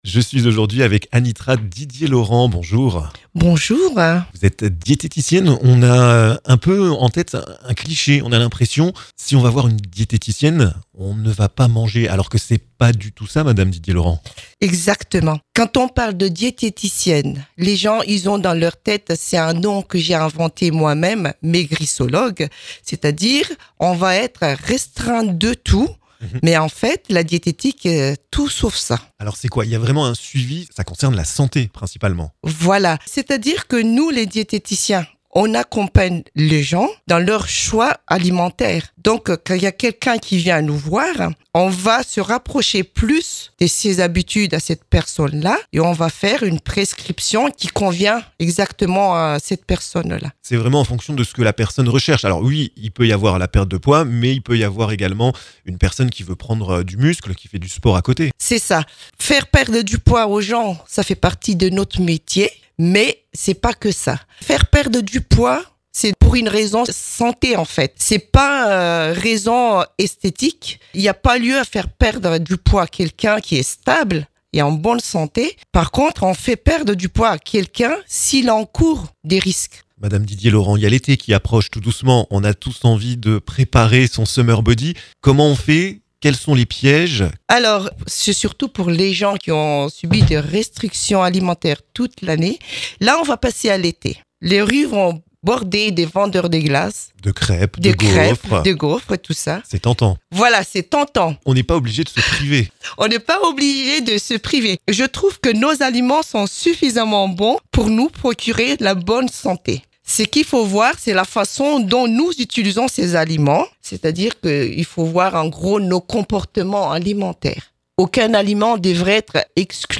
a eu la gentillesse de passer dans nos studios pour vous donner des conseils pour l'été! Et contrairement aux idées reçues, pas la peine de se priver à 100% pour être au top sur la plage!